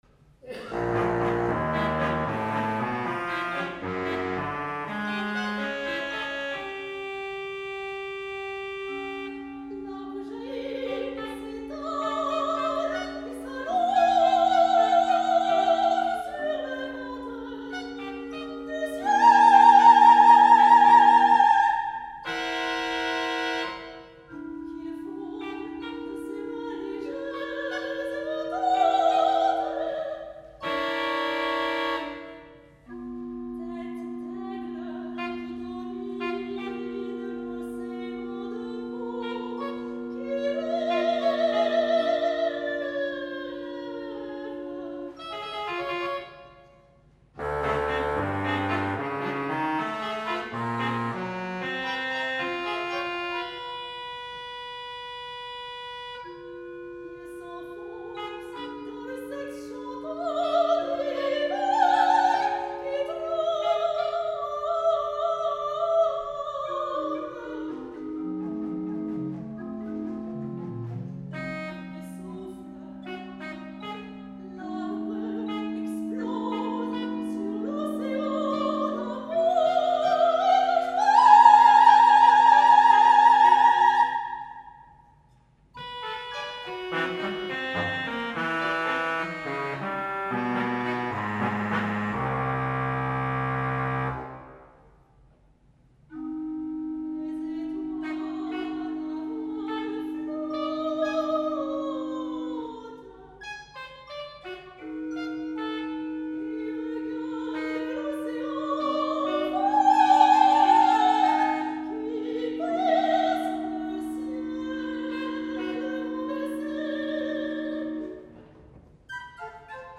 chanteuse lyrique francaise, soprano, soliste.